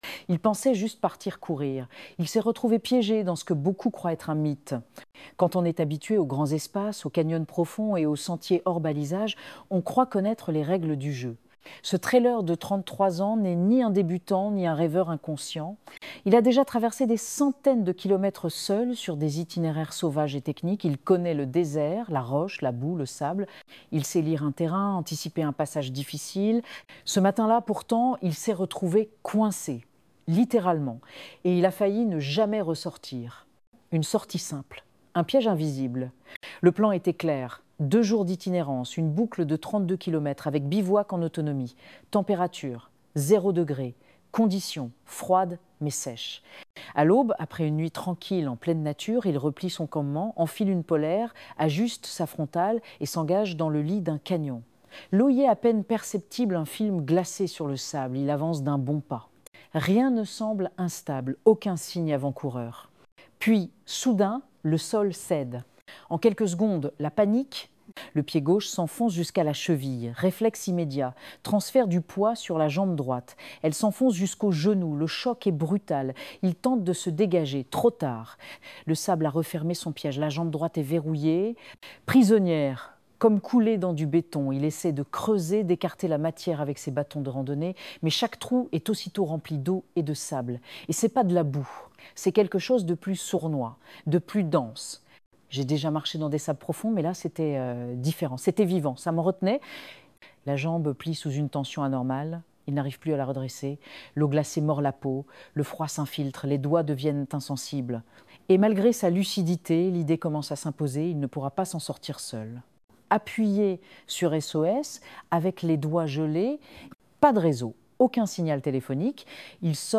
Ecouter cet article sur ce traileur secouru